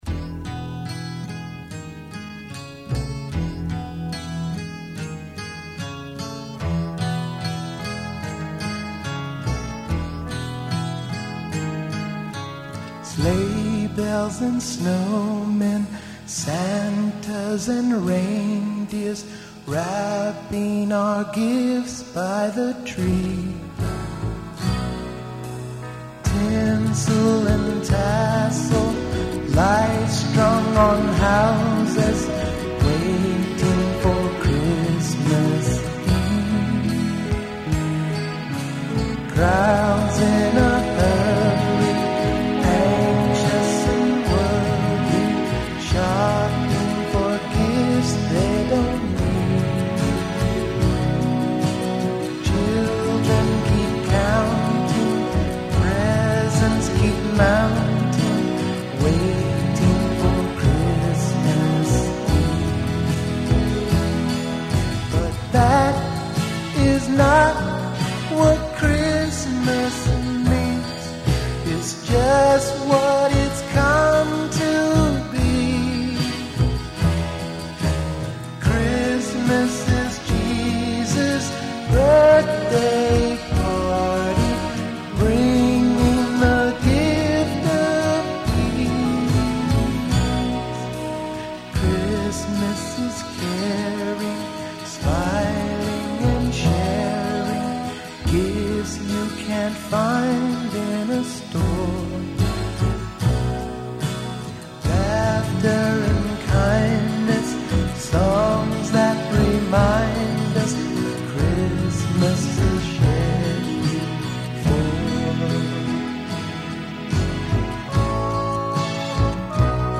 Vocal, 12 String Guitar, Bass, Keyboards, Drum Machine
Backing Vocals